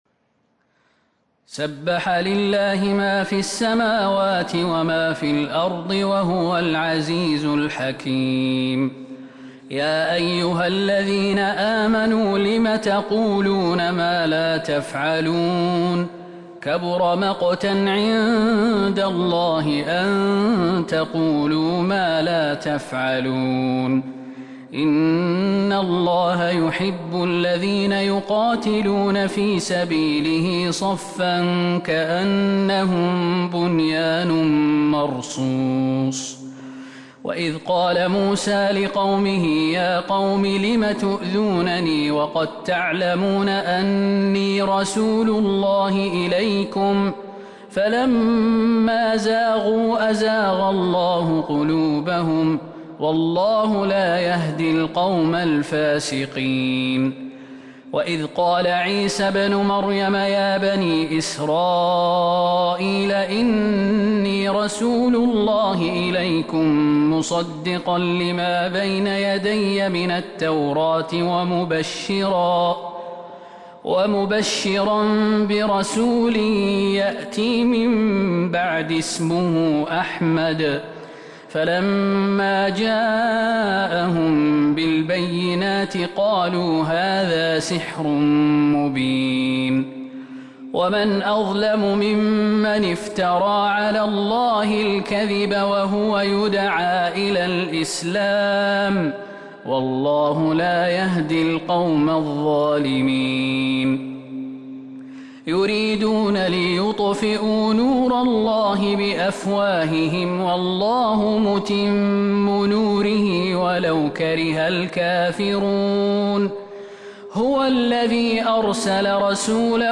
سورة الصف Surat As-Saff من تراويح المسجد النبوي 1442هـ > مصحف تراويح الحرم النبوي عام 1442هـ > المصحف - تلاوات الحرمين